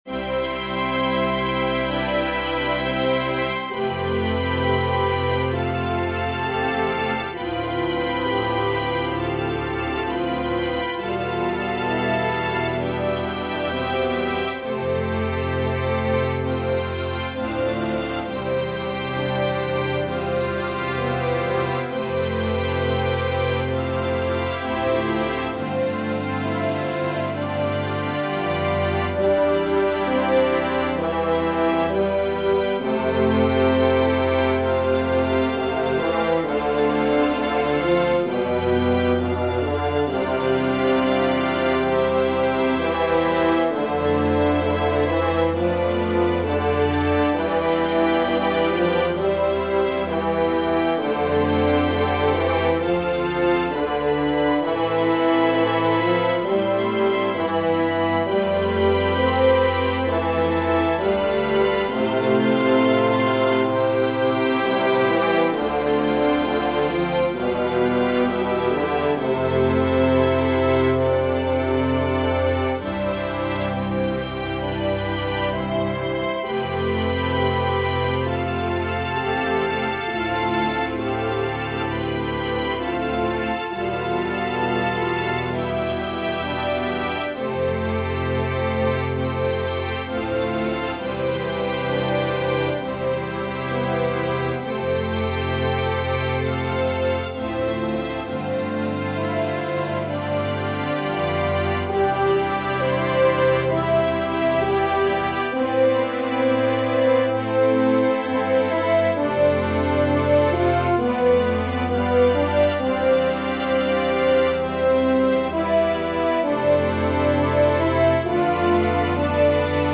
Organ/Organ Accompaniment
This is a trio piece with organ and two french horns.